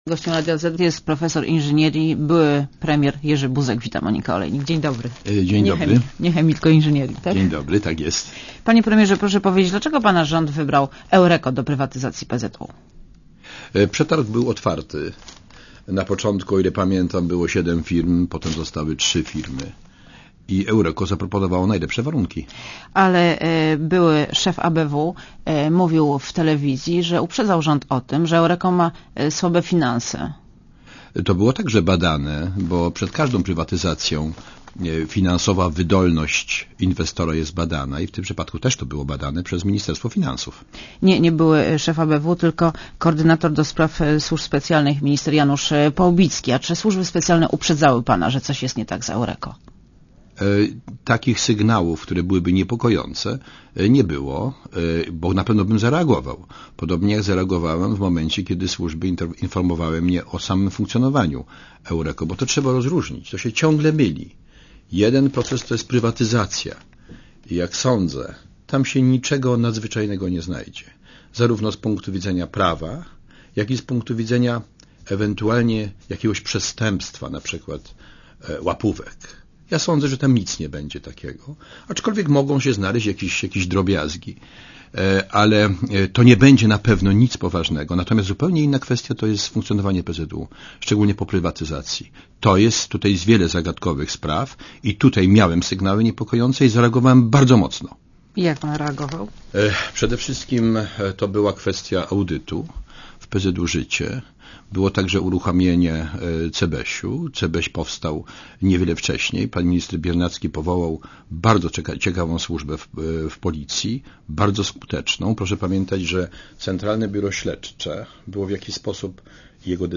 * Posłuchaj wywiadu * Gościem Radia Zet jest profesor inżynierii, były premier Jerzy Buzek .